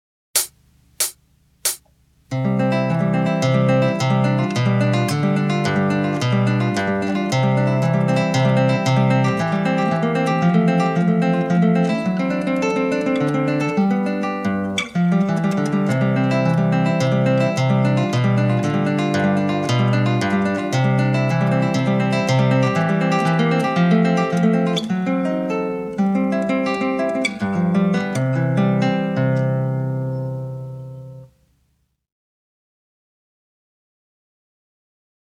Méthode pour Guitare